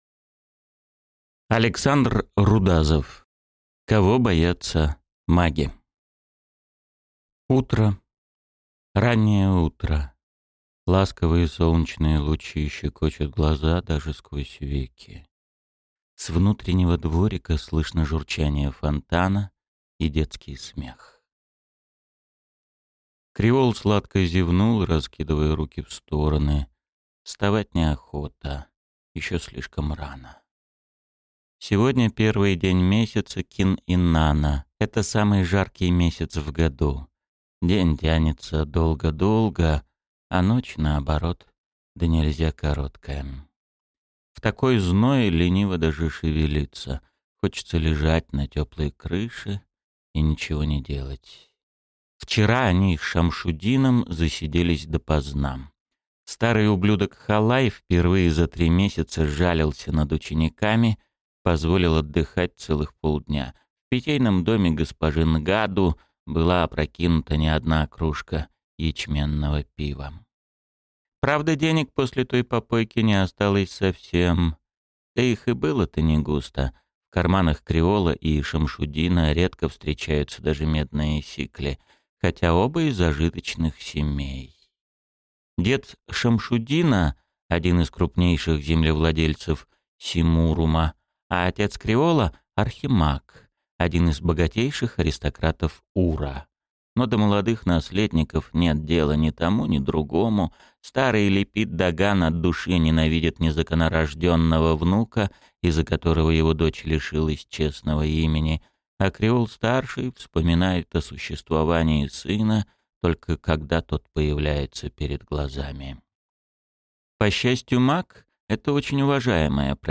Аудиокнига Кого боятся маги | Библиотека аудиокниг